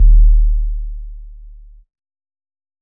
Kick Groovin 3.wav